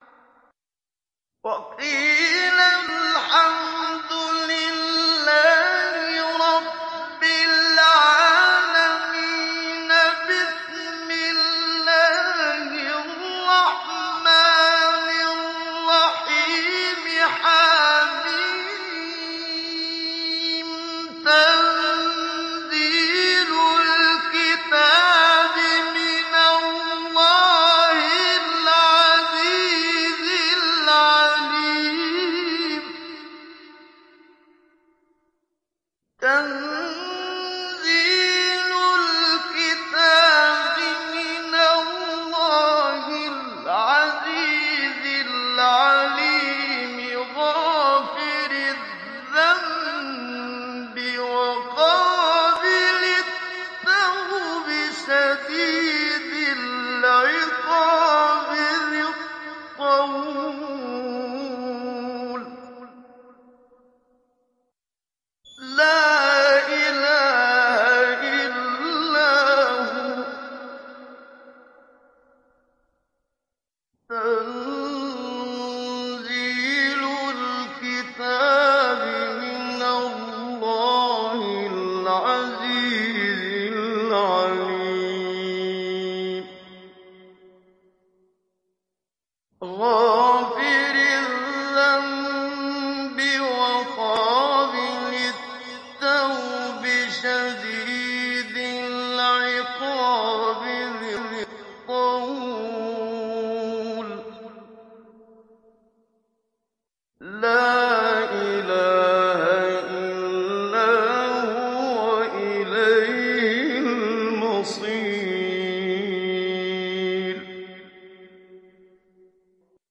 ডাউনলোড সূরা গাফের Muhammad Siddiq Minshawi Mujawwad